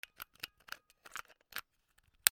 ポータブルラジオ 電池の出し入れ
『カチャパチ』